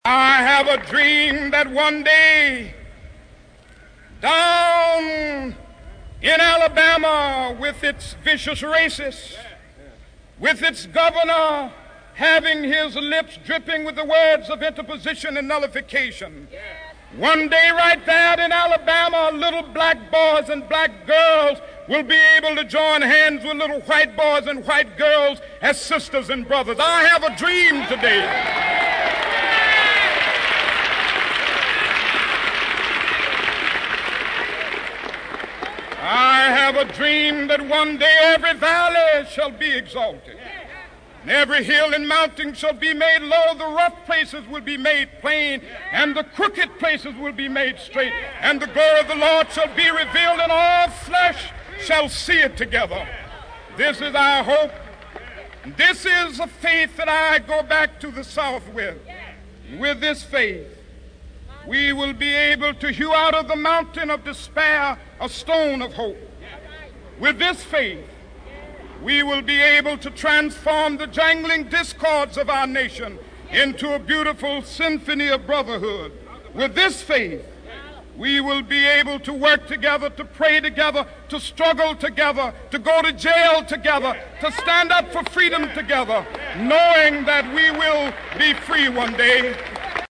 在线英语听力室名人励志英语演讲 第65期:我有一个梦想(8)的听力文件下载,《名人励志英语演讲》收录了19篇英语演讲，演讲者来自政治、经济、文化等各个领域，分别为国家领袖、政治人物、商界精英、作家记者和娱乐名人，内容附带音频和中英双语字幕。